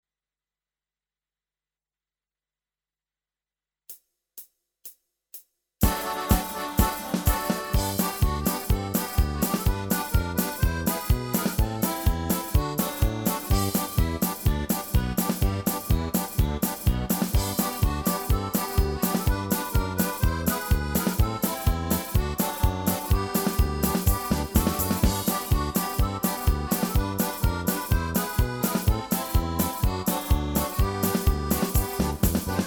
Slovenská ľudoválinka
Rubrika: Národní, lidové, dechovka
- polka